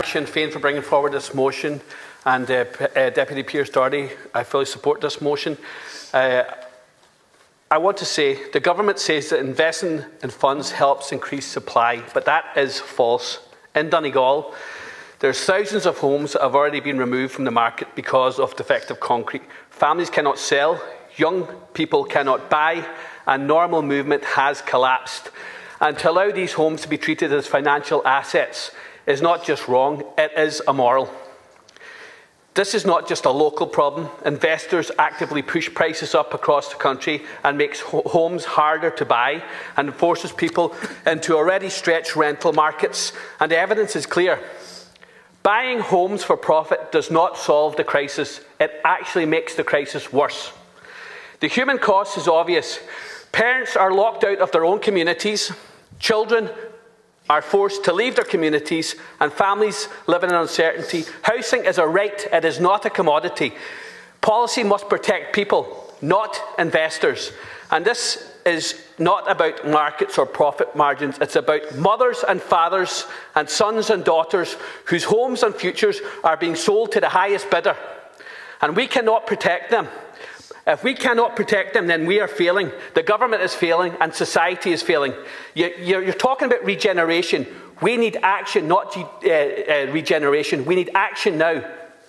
Deputy Charles Ward was speaking in the Dáil earlier this week, where he highlighted the homes that remain unsafe and unsellable, due to “Government inaction”.